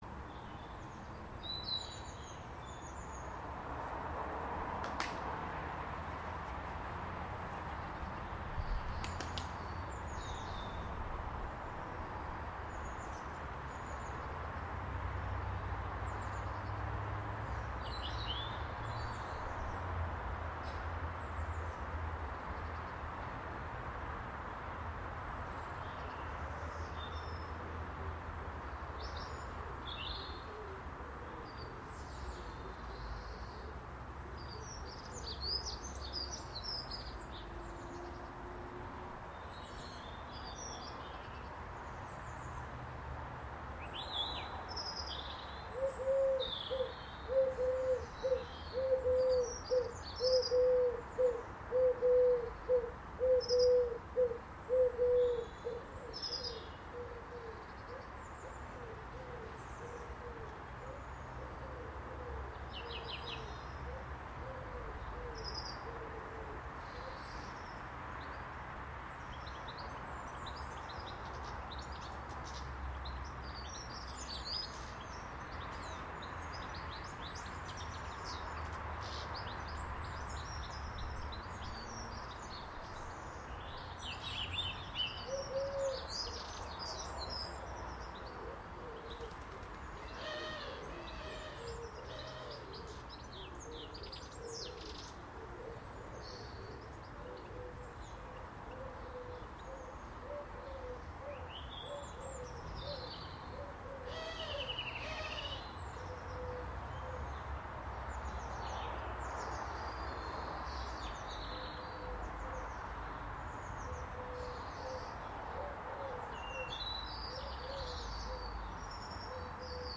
three and a half minutes of birdsong in parents' back garden at lunchtime